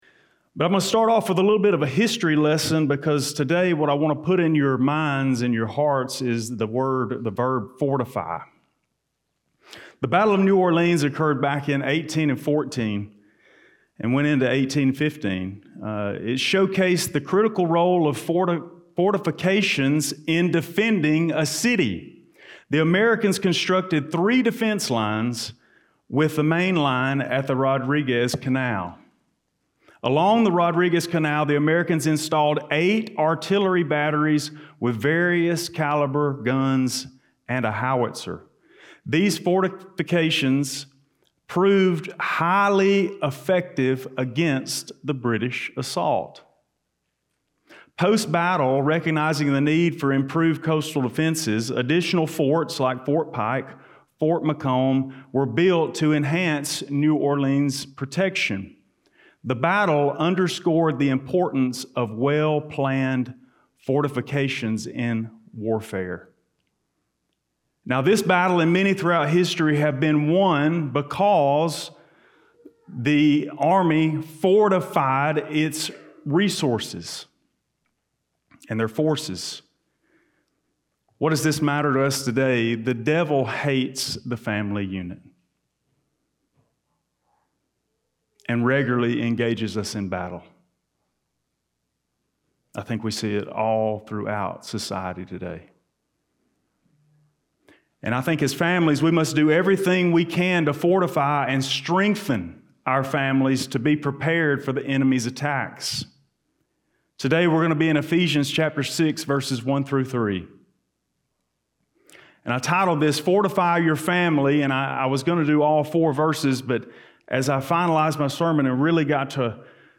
This sermon will focus upon the first 3 verses which instruct children of parents. Although the instruction is for children, parents are instructed as well through what the children should be taught and led to do.